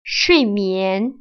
[shuìmián] 수이미앤